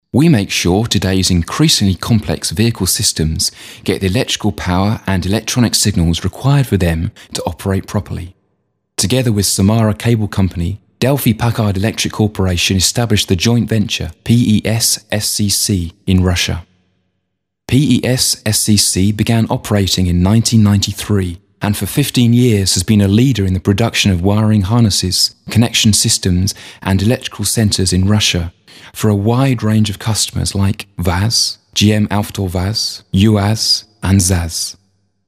Аудио для презентации (английский язык) Категория: Аудио/видео монтаж
Фрагмент аудио для презентации, английский язык, диктор – носитель языка.